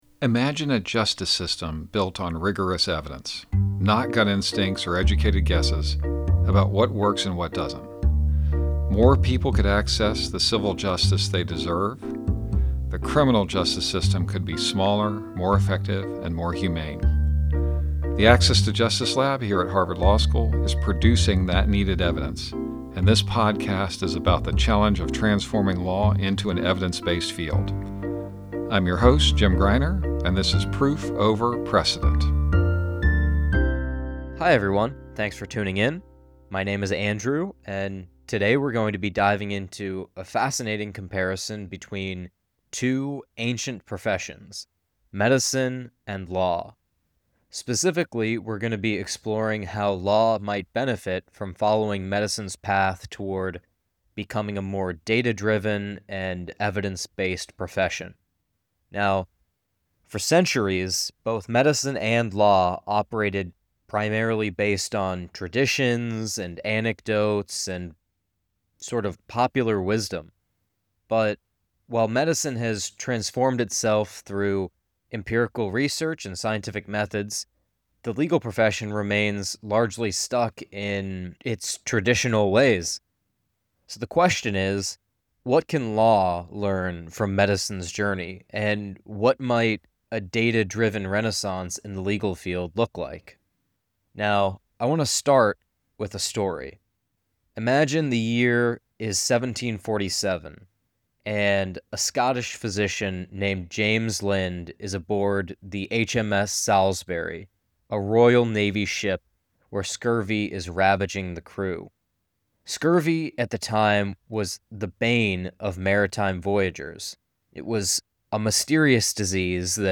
The Access to Justice Lab at Harvard Law School discusses the work of bringing credible evidence to lawyers, judges, and decision makers, to transform the U.S. justice system into an evidence-based field. We bring you weekly one-on-one interviews with experts in the area of access to justice -- researchers, lawyers, professors, law students, data analysts, research participants, and anyone who has an interesting role in this growing area.